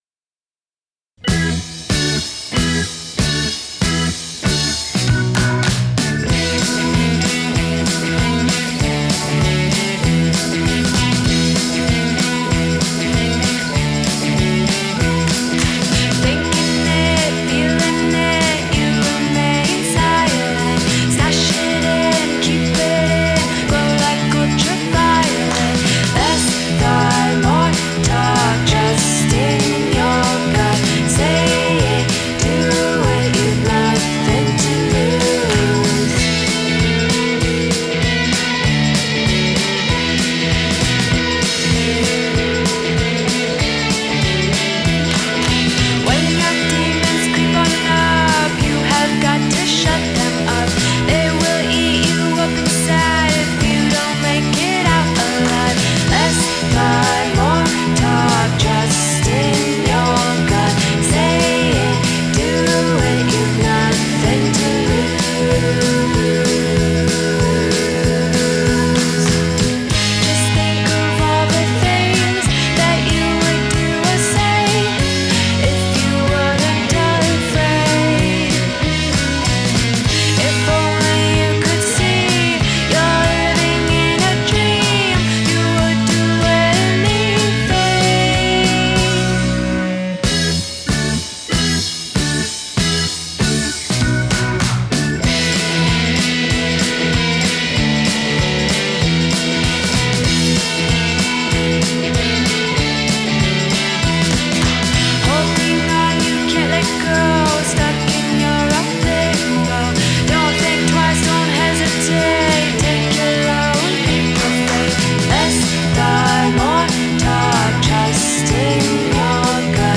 Genre: indie pop, dream pop, indie rock